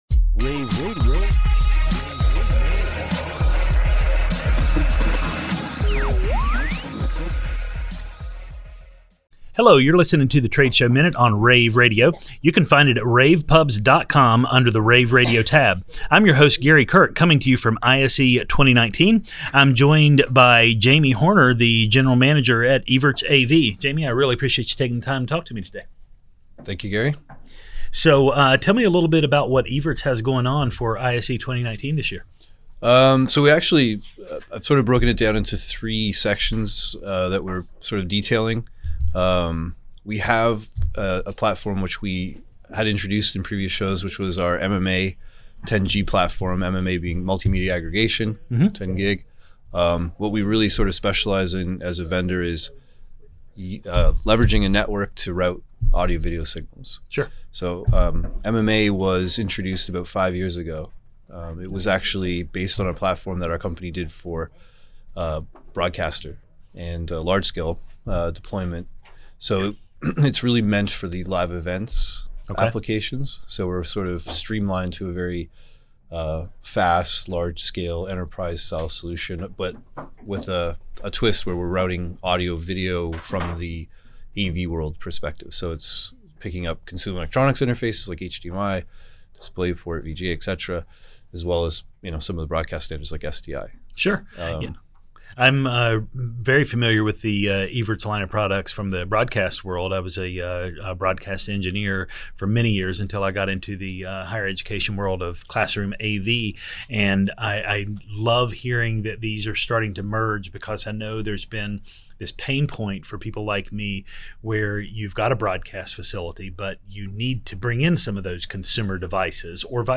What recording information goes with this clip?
February 5, 2019 - ISE, ISE Radio, Radio, rAVe [PUBS], The Trade Show Minute,